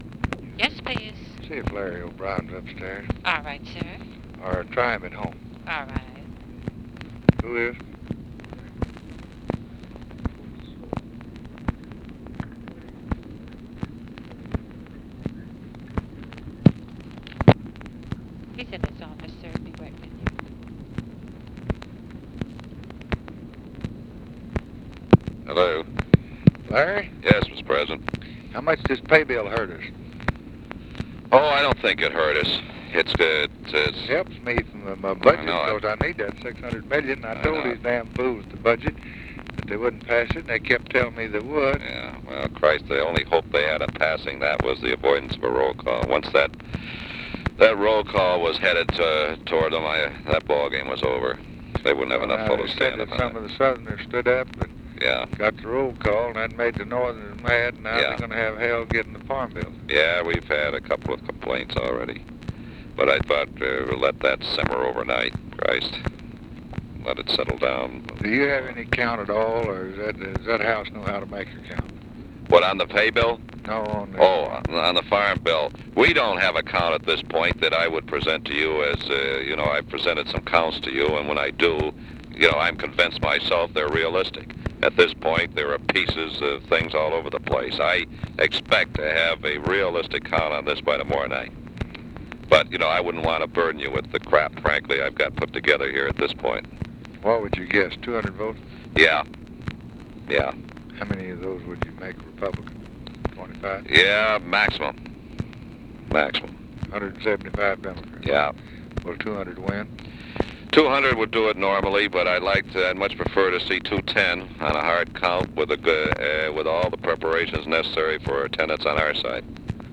Conversation with LARRY O'BRIEN, March 13, 1964
Secret White House Tapes